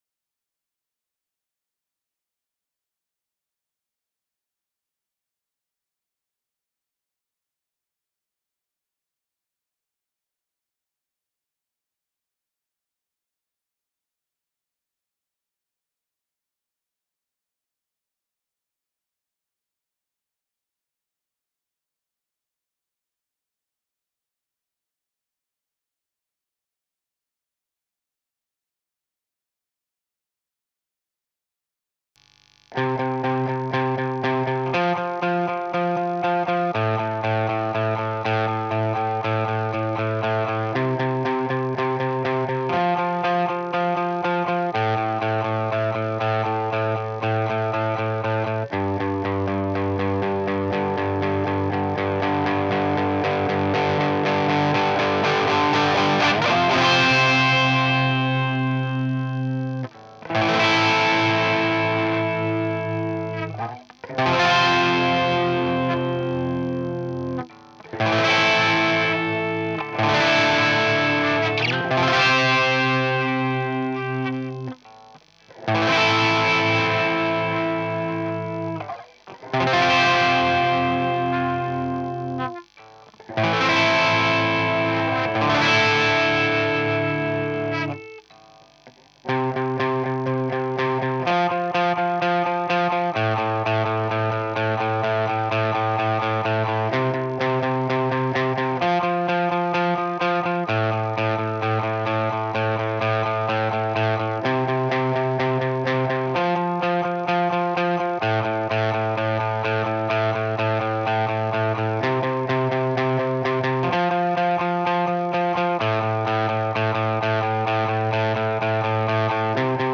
White Git3.wav